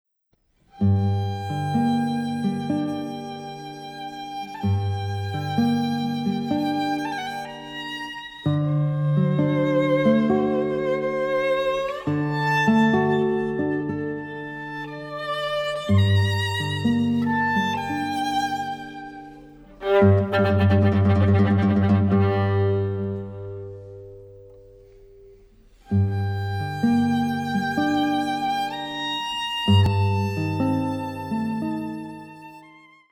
Geige
Klavier
Schlagwerker